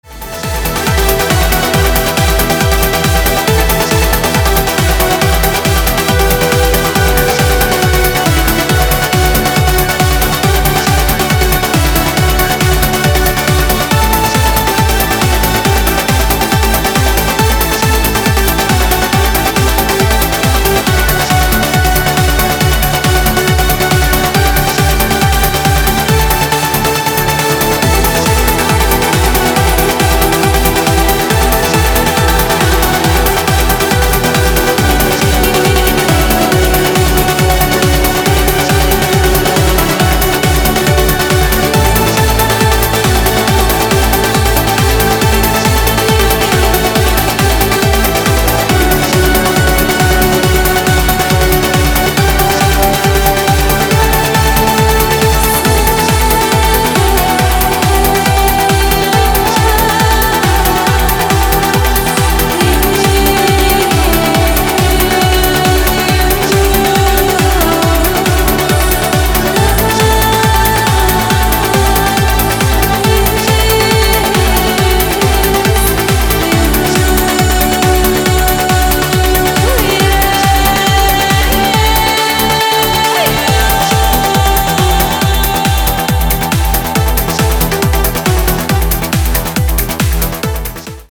• Качество: 320, Stereo
красивые
club
энергичные
Trance
динамичные